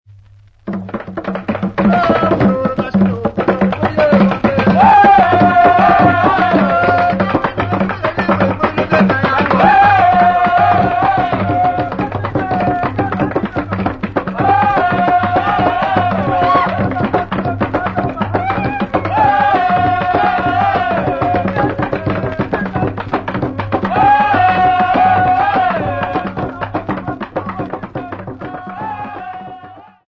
A15-01.mp3 of M'Bandjas dance